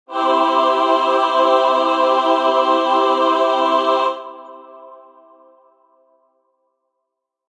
Download Free Angel Sound Effects